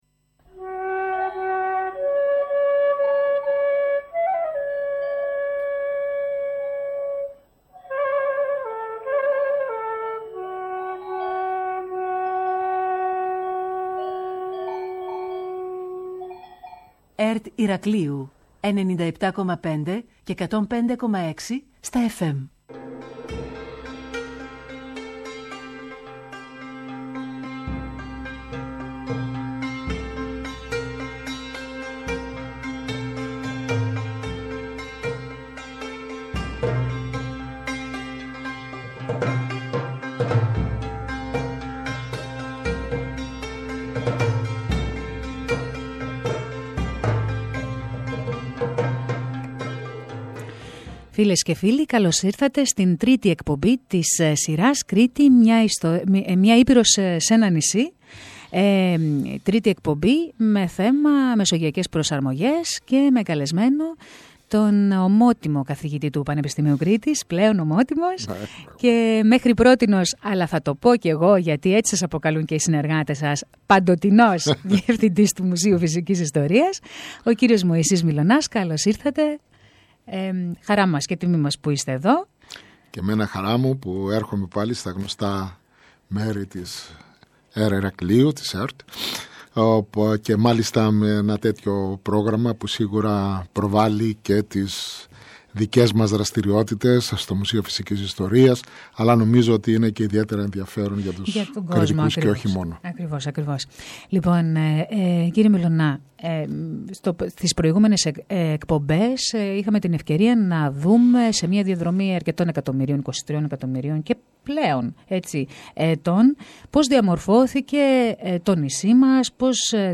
Ακολουθεί ολόκληρη η εκπομπή με τίτλο «Μεσογειακές Προσαρμογές» που μεταδόθηκε σήμερα, 11-12 π.μ., από την ΕΡΤ Ηρακλείου.